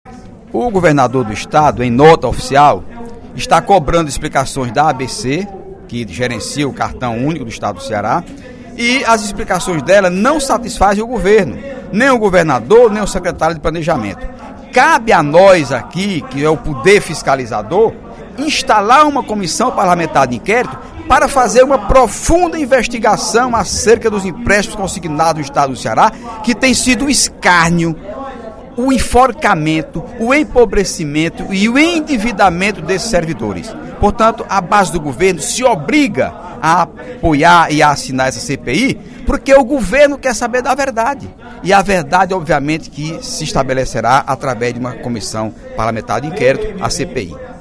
O deputado Heitor Férrer (PDT) pediu nesta terça-feira (10/04), em sessão plenária, a abertura de uma Comissão Parlamentar de Inquérito (CPI) para “fazer uma profunda investigação” acerca dos consignados para servidores do Estado.